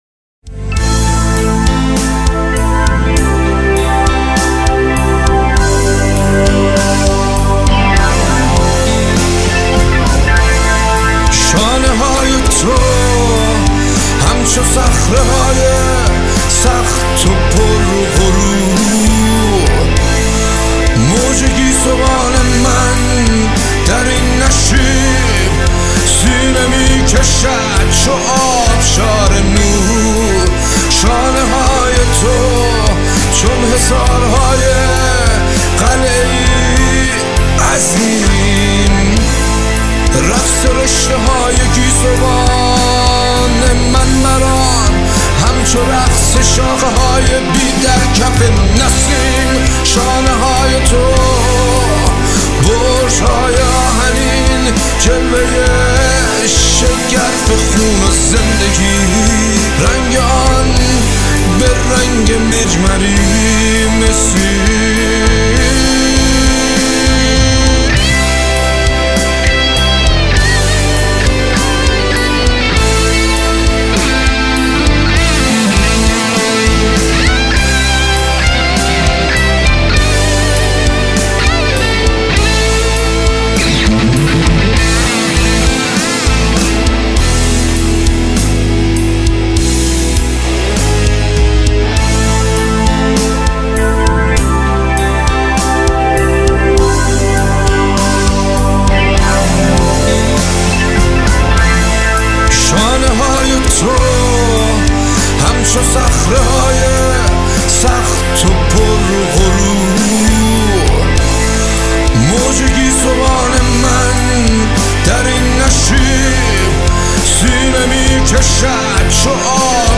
Contemporary